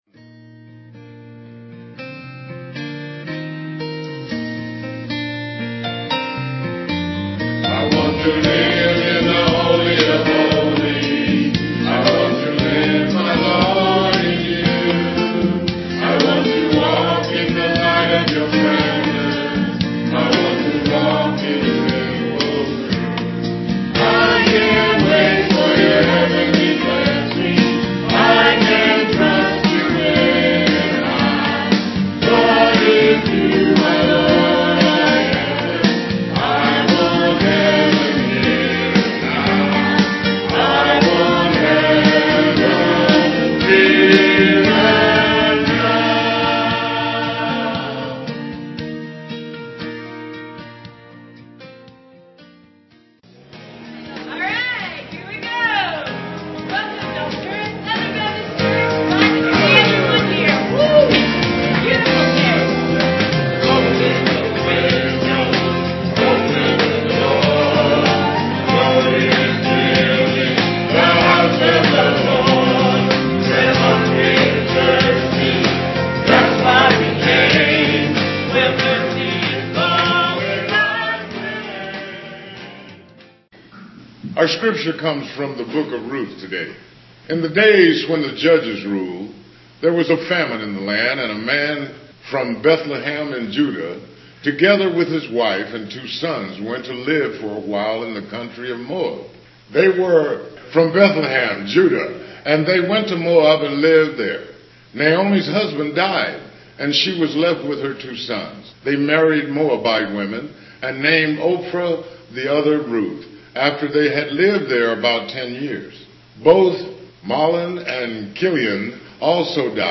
Piano/organ offertory